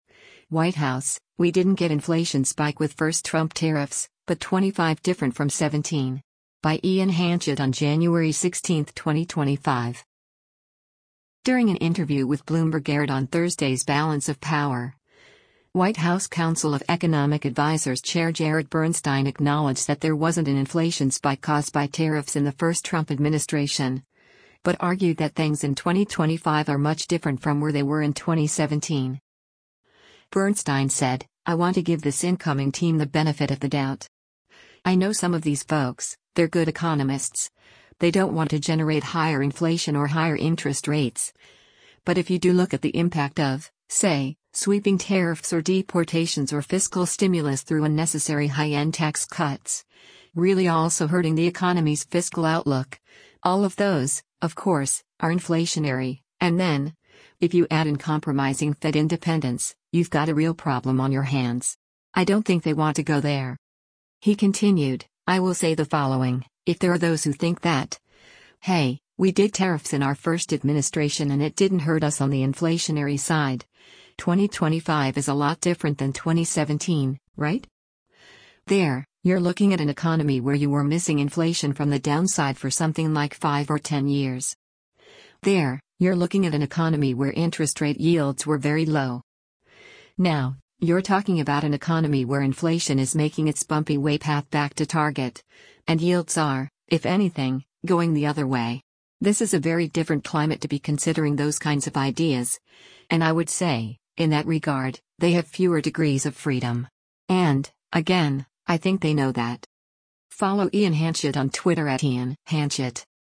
During an interview with Bloomberg aired on Thursday’s “Balance of Power,” White House Council of Economic Advisers Chair Jared Bernstein acknowledged that there wasn’t an inflation spike caused by tariffs in the first Trump administration, but argued that things in 2025 are much different from where they were in 2017.